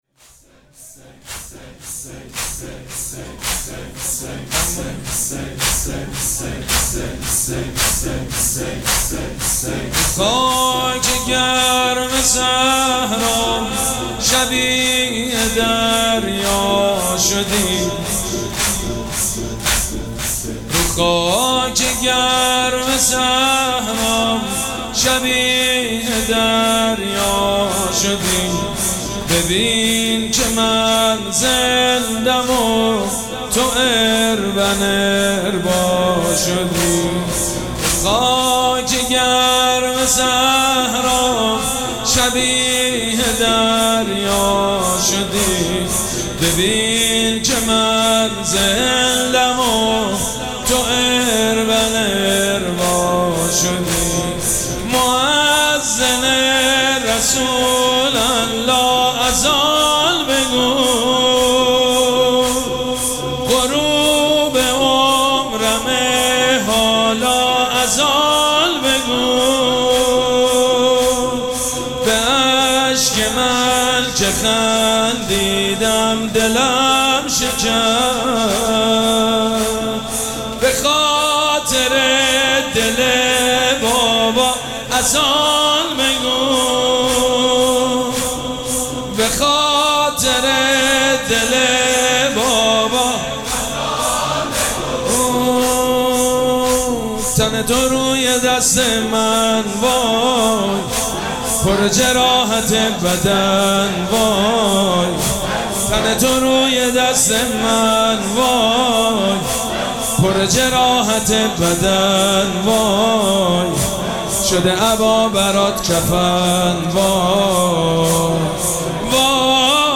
مراسم عزاداری شب هشتم محرم الحرام ۱۴۴۷
مداح
حاج سید مجید بنی فاطمه